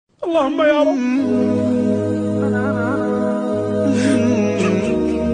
Allahumme Yarab ses efekti - Türkçe MP3 ses dosyası